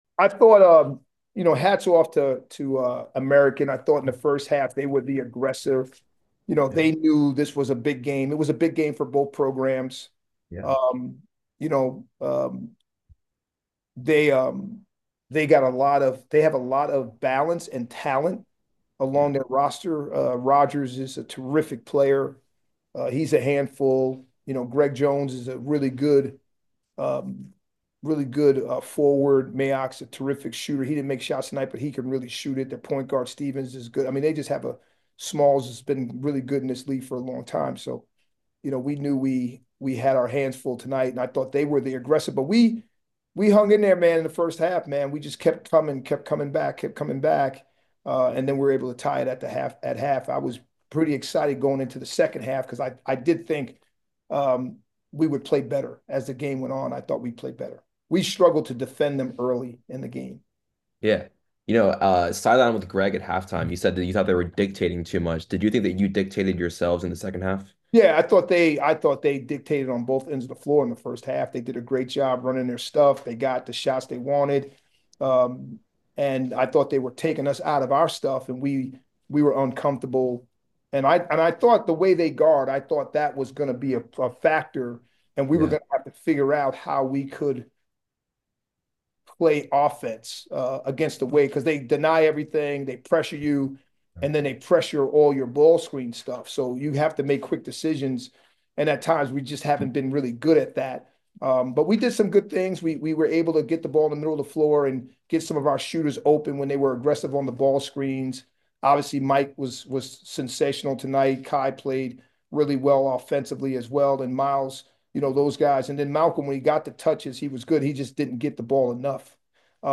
Men's Basketball / American Postgame Interview